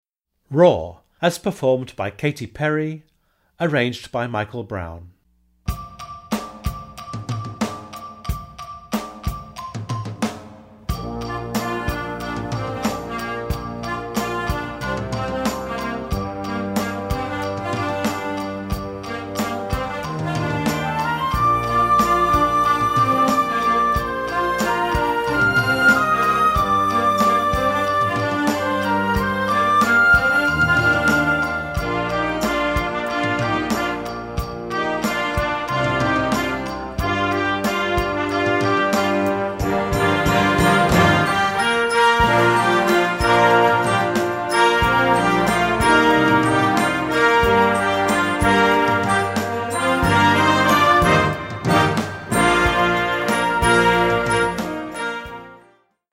POP & ROCK TUNES Grade 2.0